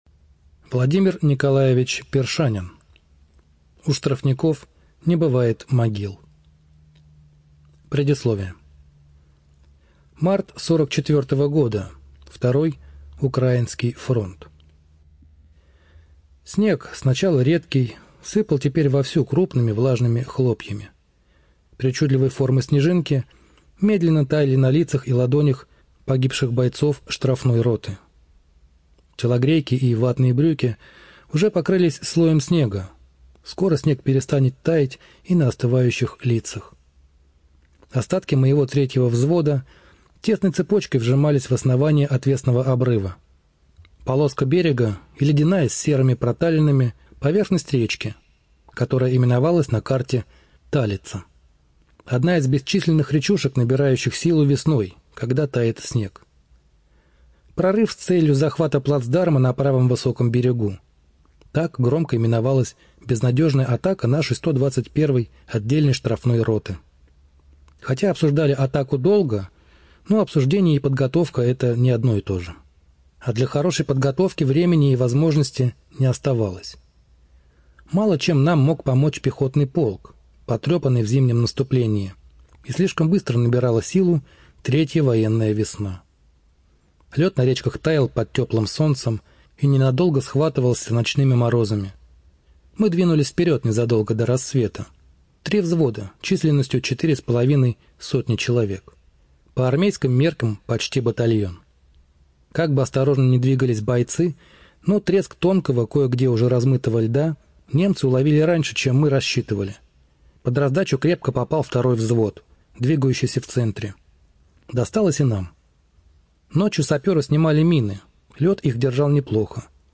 ЖанрВоенная литература